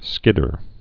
(skĭdər)